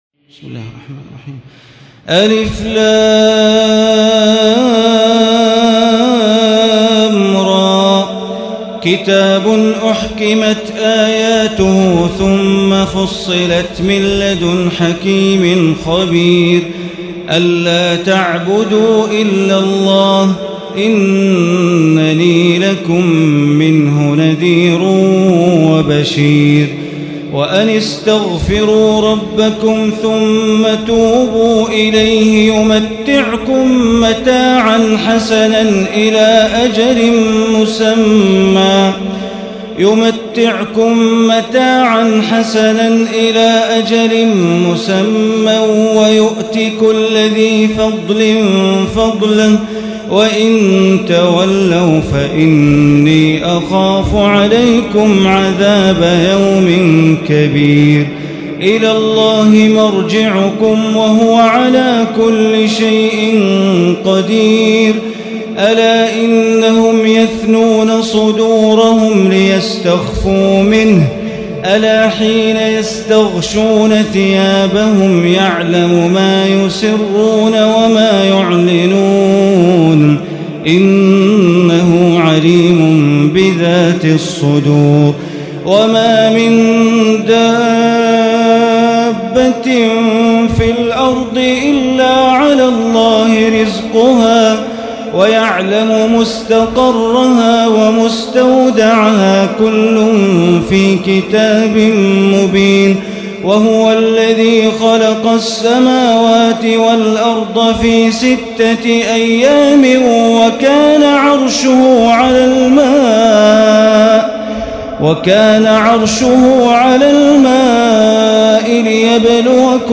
ليلة 11 من رمضان عام 1436 من سورة هود الآية 01 إلى الآية 95 > تراويح ١٤٣٦ هـ > التراويح - تلاوات بندر بليلة